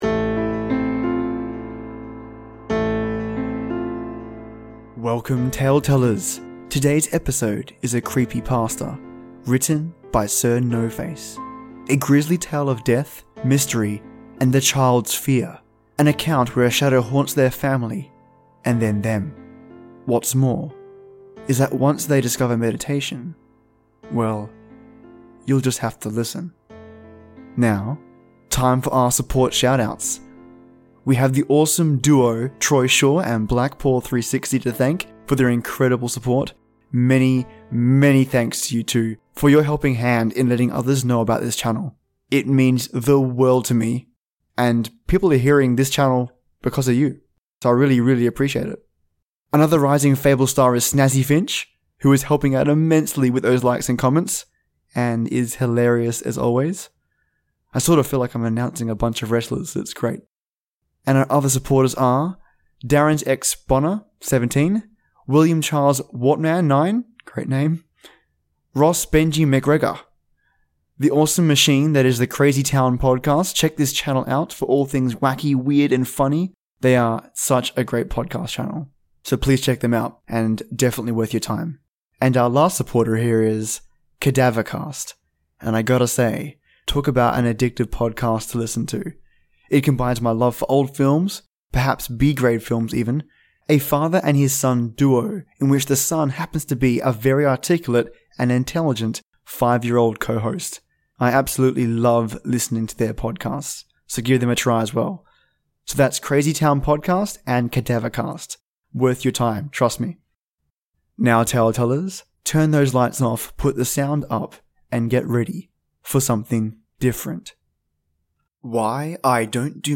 Sinister Dark Music